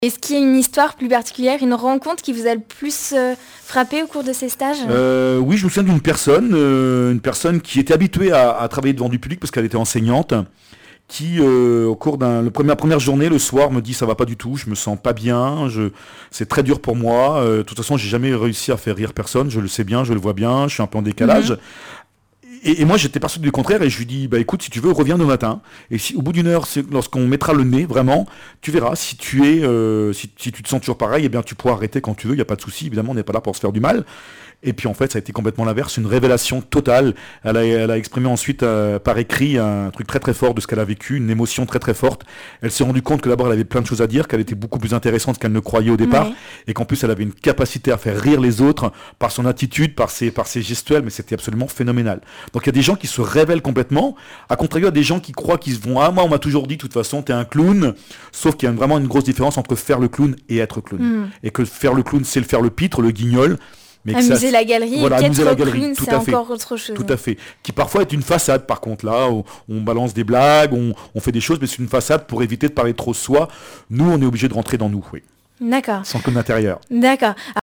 émission de RCF Vendée sur les activités de Libr'arthé
Témoignage